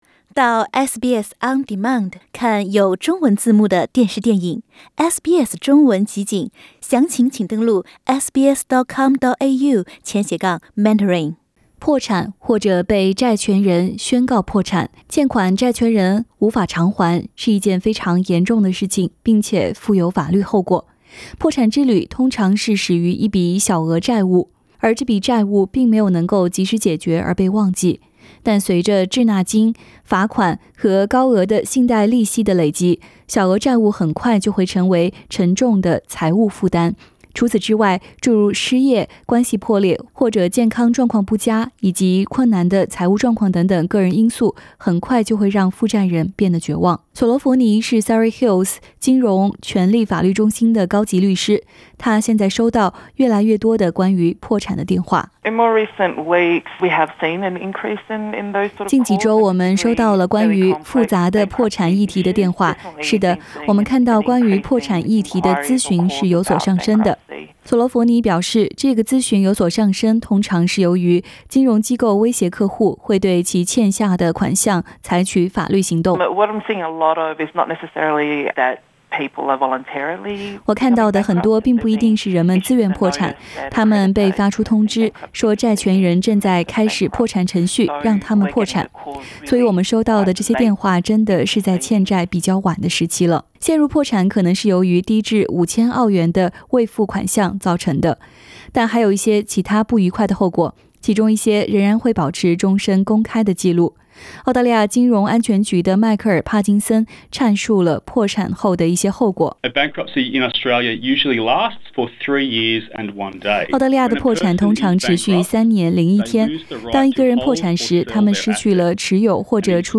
SBS Mandarin